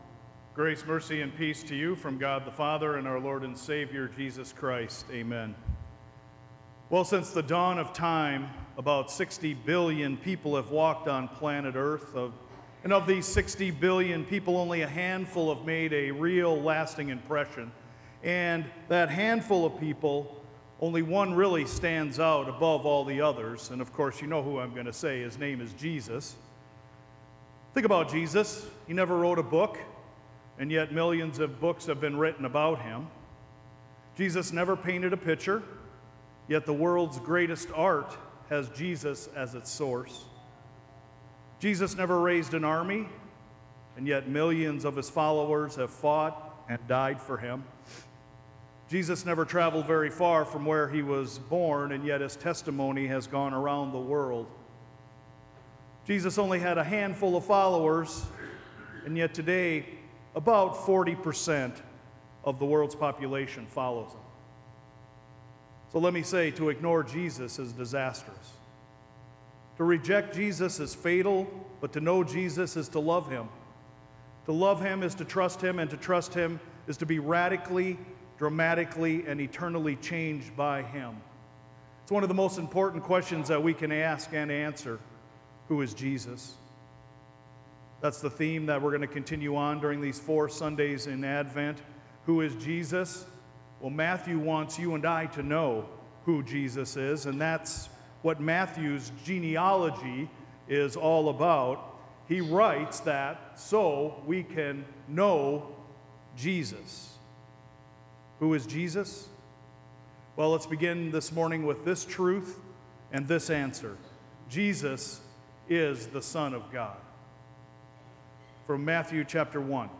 ZION-SERVICE_DECEMBER_1_2024_SERMON.mp3